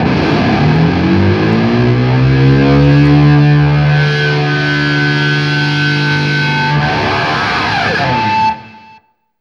DIVEBOMB15-L.wav